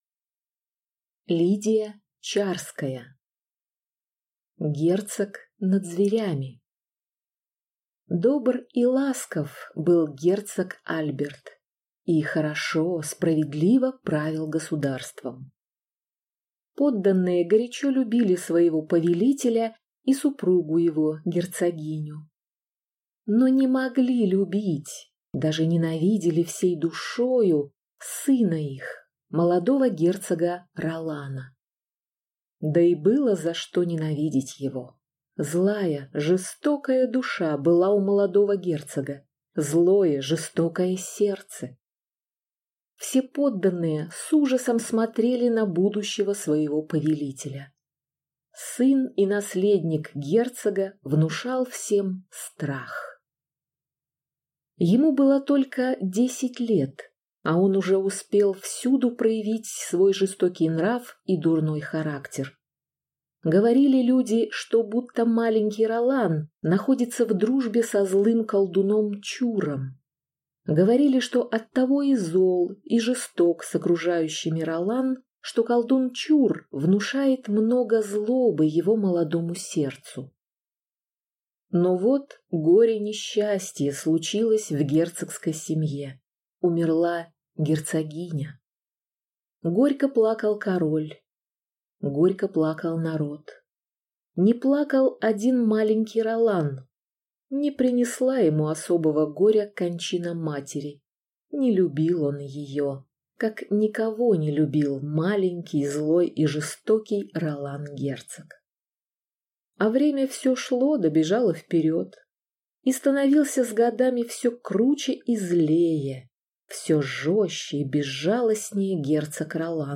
Аудиокнига Герцог над зверями | Библиотека аудиокниг